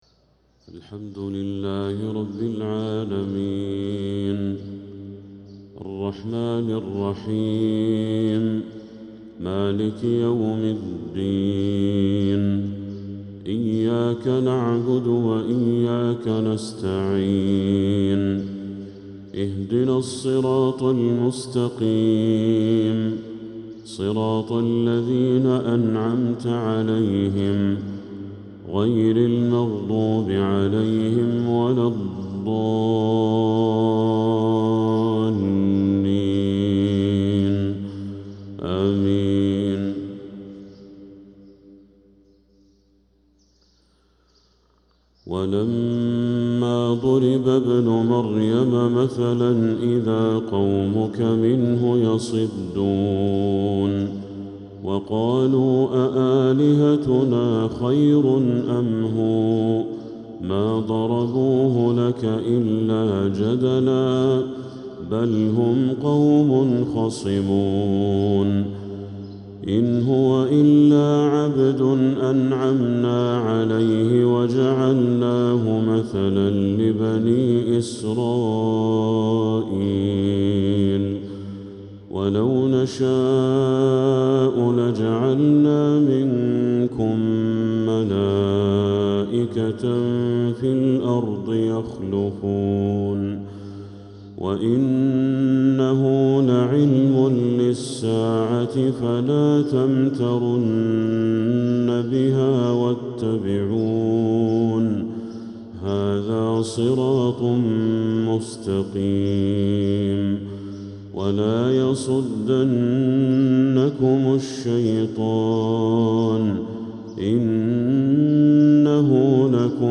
فجر السبت 4-7-1446هـ خواتيم سورة الزخرف 57-89 | Fajr prayer from Surat Az-Zukhruf 4-1-2025 🎙 > 1446 🕋 > الفروض - تلاوات الحرمين